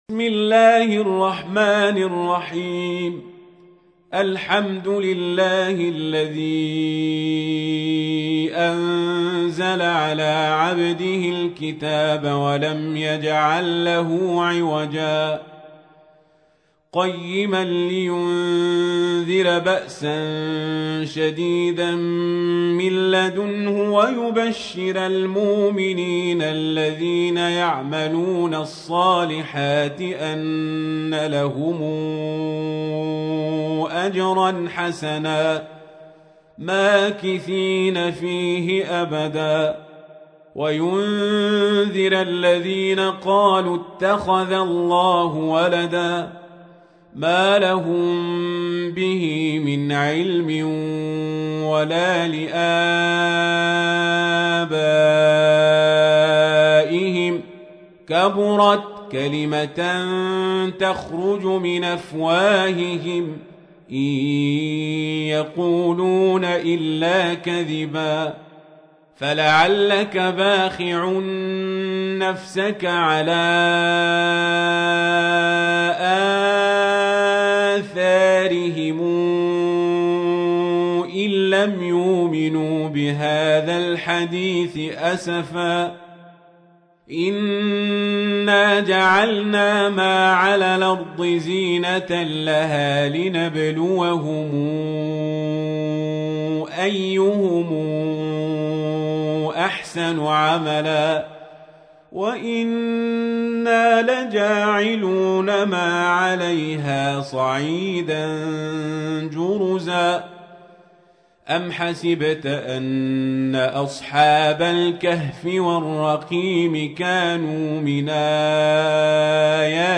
تحميل : 18. سورة الكهف / القارئ القزابري / القرآن الكريم / موقع يا حسين